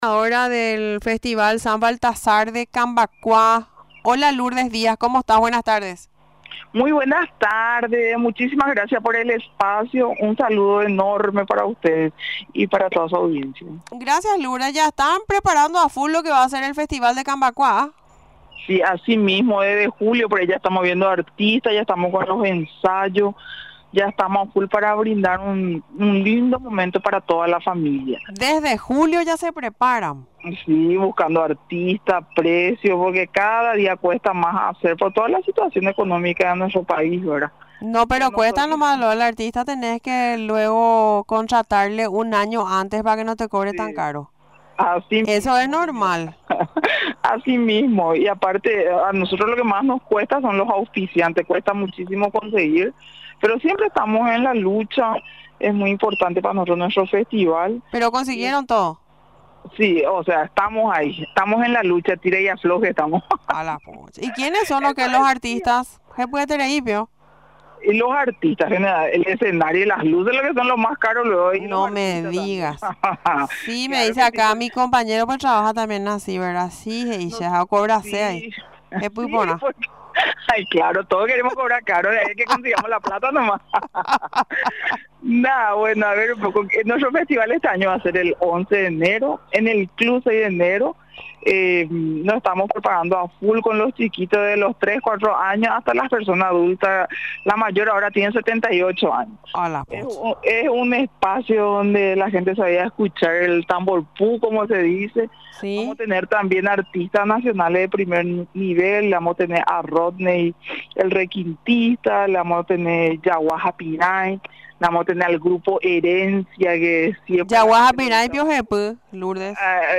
Durante la entrevista en Radio Nacional del Paraguay, explicó los detalles de la organización. Además citó a las agrupaciones que se presentarán en una edición más del festival. Finalmente, invitó a la ciudadanía en general para asistir al Festival.